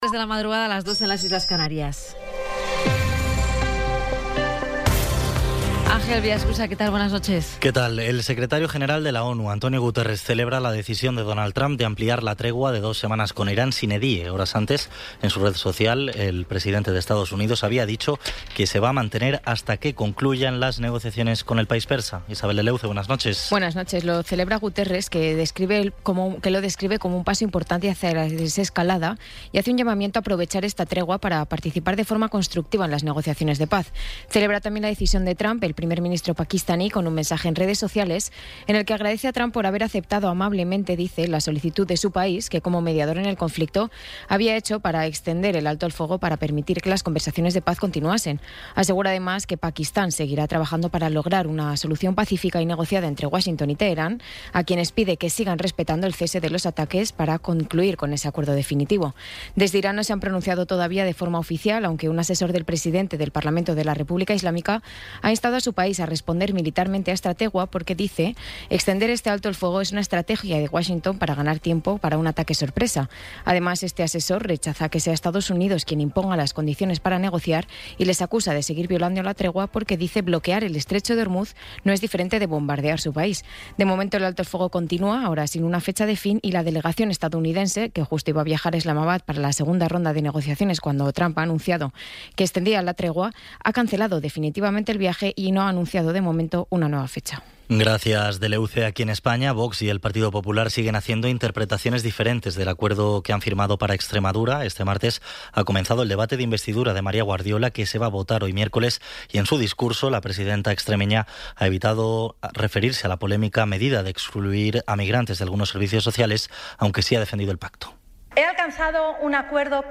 Resumen informativo con las noticias más destacadas del 22 de abril de 2026 a las tres de la mañana.